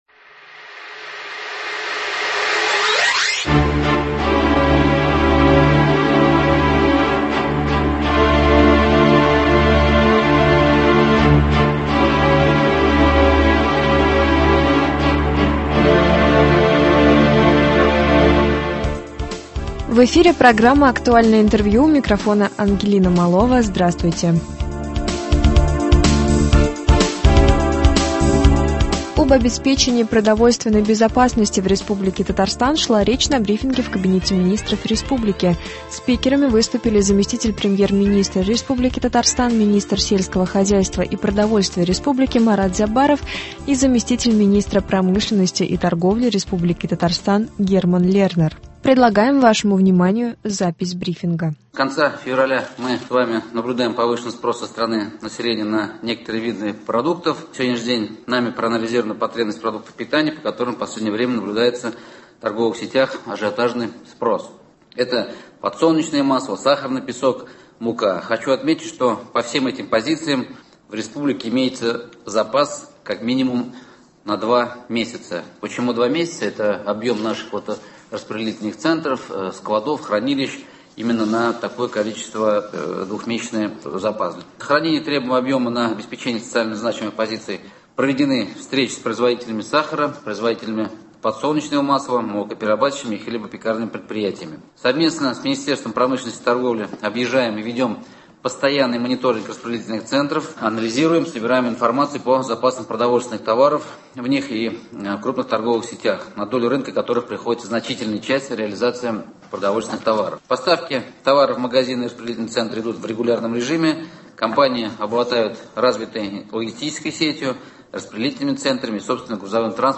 Актуальное интервью (16.03.22)